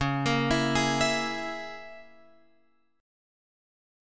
C#m6 chord